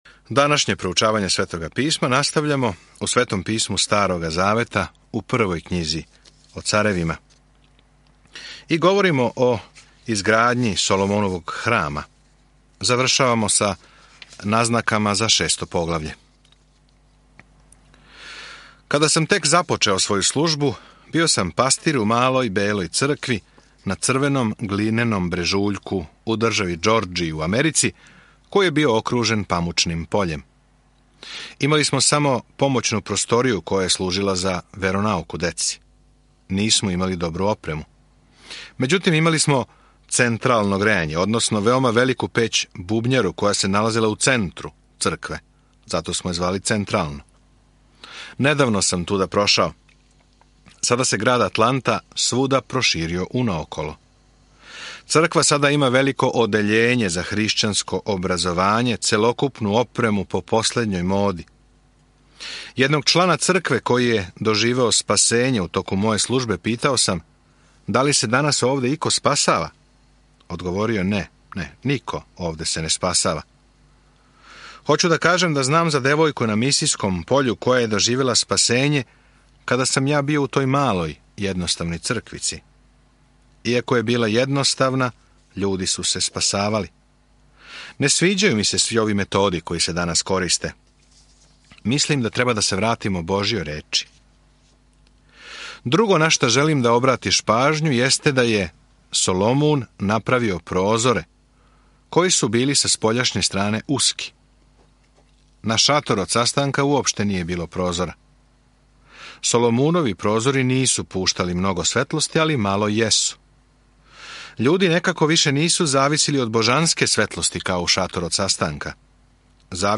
Knjiga o carevima 8:1-46 Dan 4 Započni ovaj plan Dan 6 O ovom planu Књига о Краљевима наставља причу о томе како је Израелско краљевство цветало под Давидом и Соломоном, али се на крају распршило. Свакодневно путујте кроз 1. Краљевима док слушате аудио студију и читате одабране стихове из Божје речи.